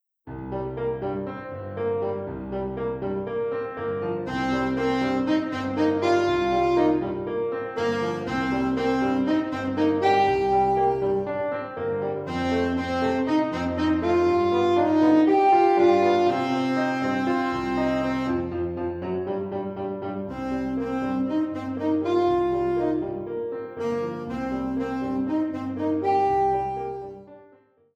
with Piano or backing tracks
Alto Saxophone and Piano